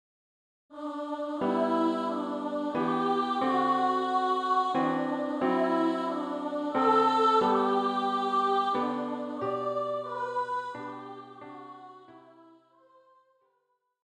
für Gesang, hohe Stimme